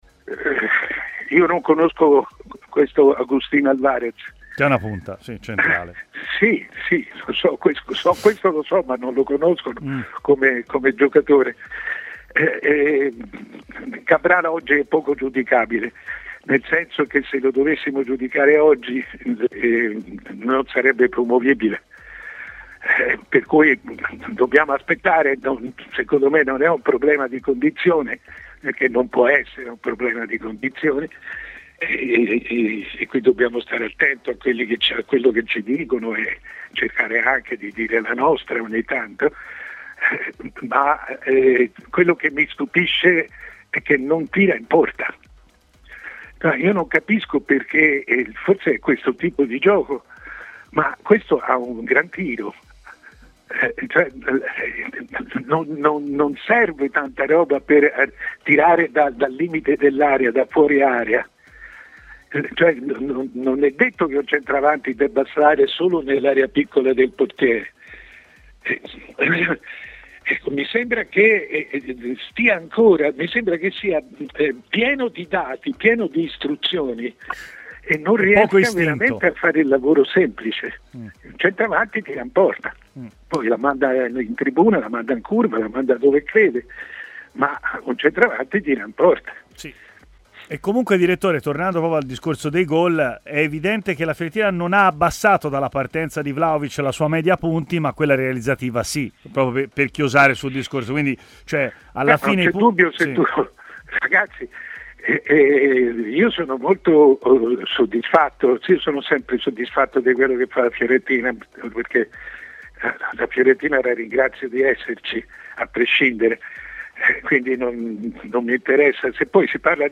Il decano dei giornalisti italiani Mario Sconcerti ha parlato anche della Fiorentina, durante la trasmisisone Stadio Aperto su TMW Radio, a partire dalla prova di Cabral domenica: